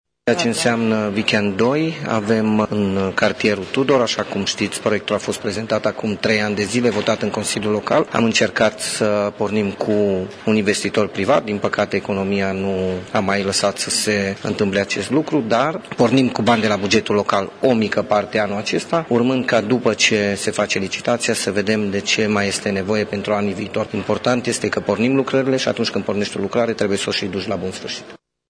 Indicatorii tehnico economici au fost aprobaţi iniţial în anul 2010, dar municipalitatea nu a reuşit, datorită crizei economice, să înceapă derularea proiectului, a explicat viceprimarul Claudiu Maior: